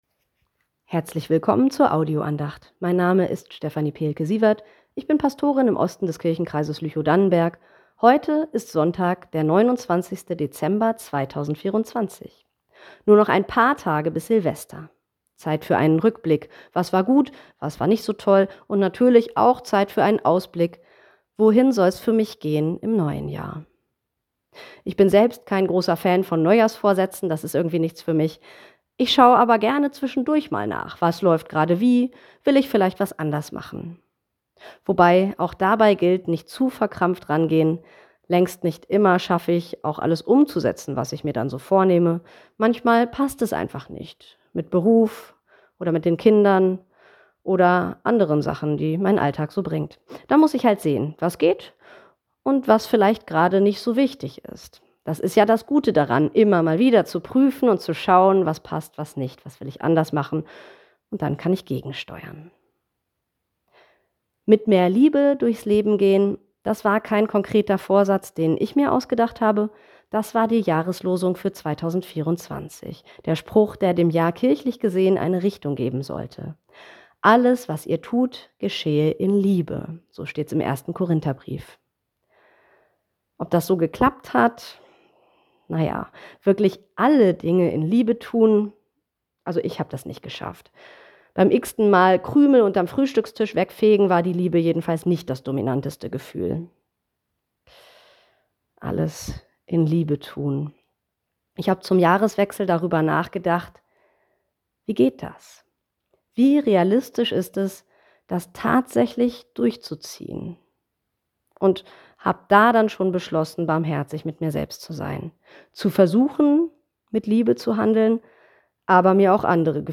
Die Liebe, das Gute und alles ~ Telefon-Andachten des ev.-luth.